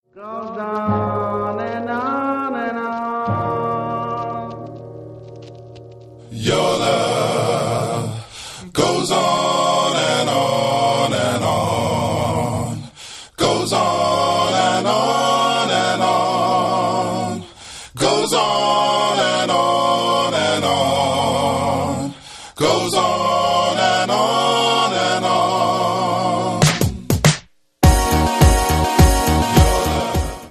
Das West Coast Trio
• Sachgebiet: Rap & HipHop